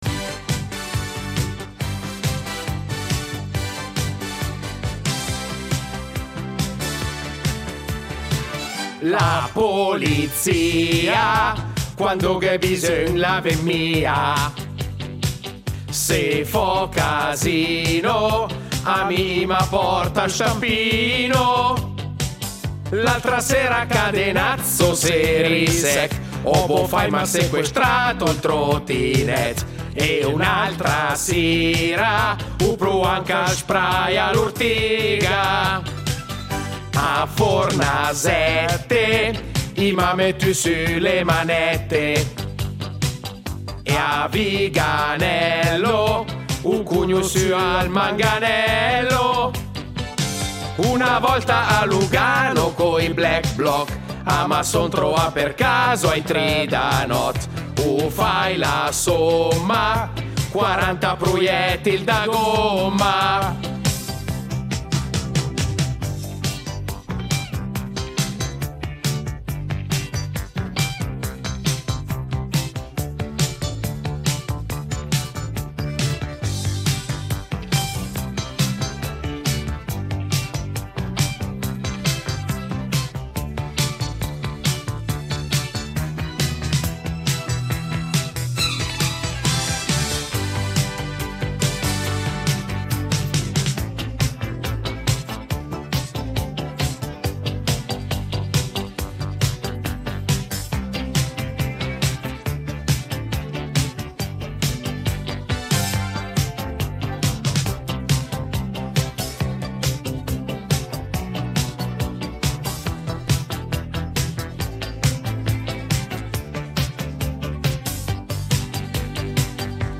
hanno cantato tutti i pregiudizi “da bar” sulla Polcant!